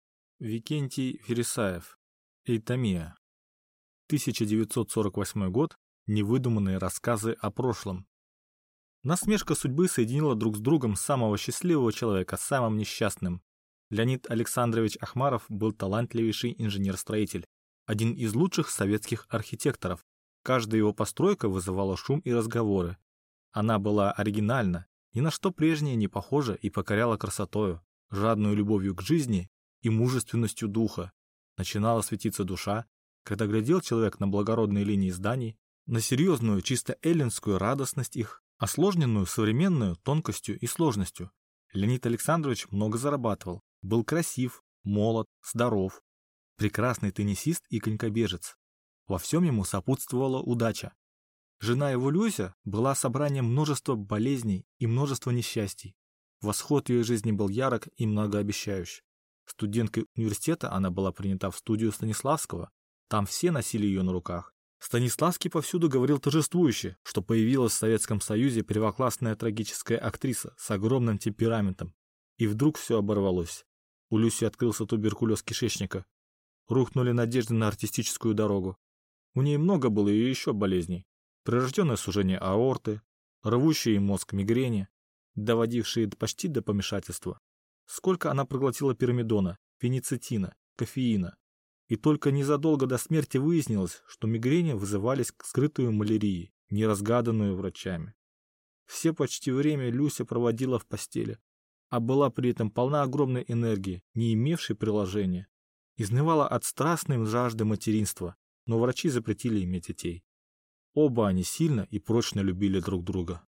Аудиокнига Euthymia | Библиотека аудиокниг
Прослушать и бесплатно скачать фрагмент аудиокниги